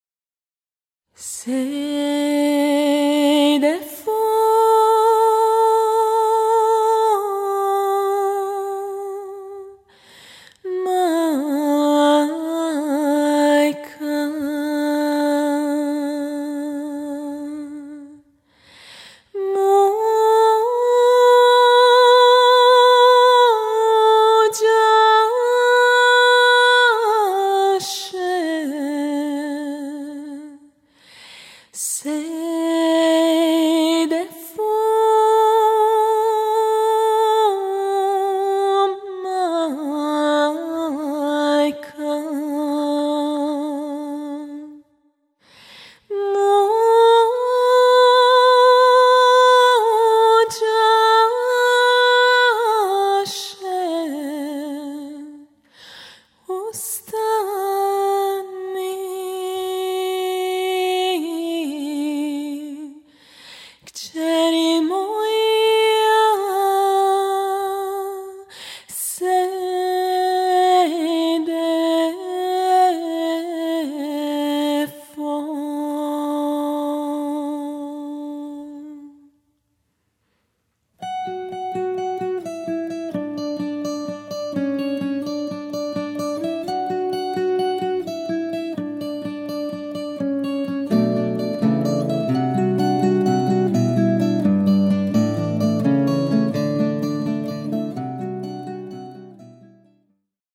klasična kitara
akustična kitara
tolkala
violina
violončelo